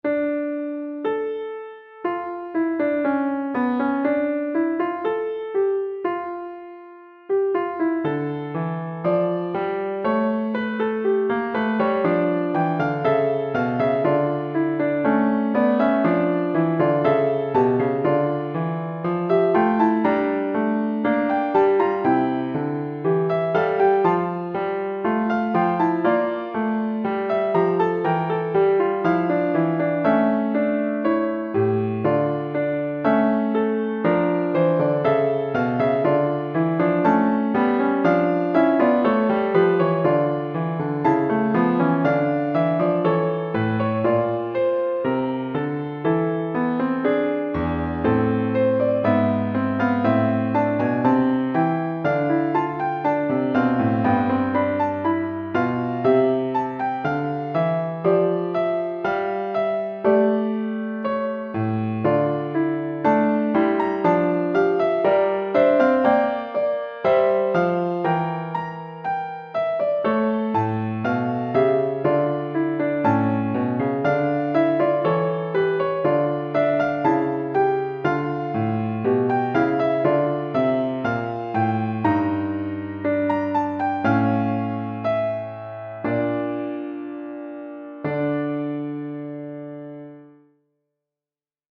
Another Fugue in d-minor - Piano Music, Solo Keyboard